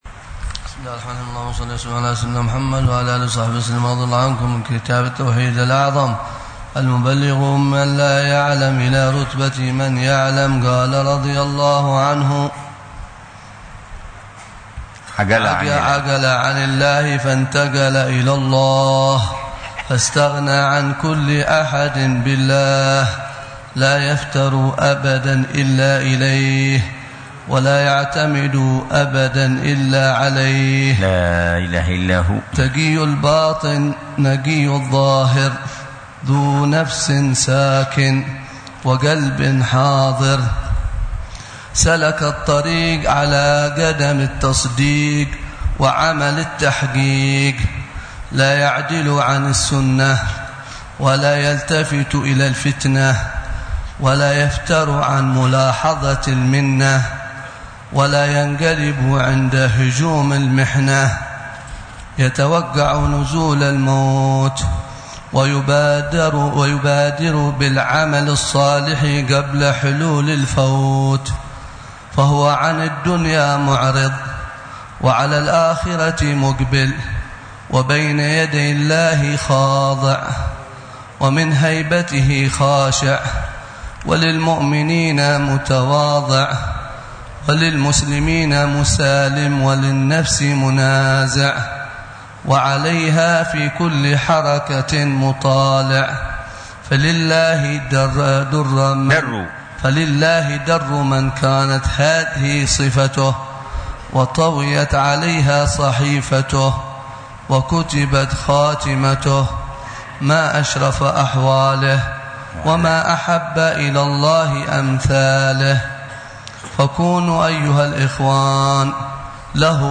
شرح الحبيب عمر بن حفيظ على كتاب: التوحيد الأعظم المبلغ من لا يعلم إلى رتبة من يعلم، للإمام الشيخ أحمد بن علوان، ضمن دروس الدورة الرابعة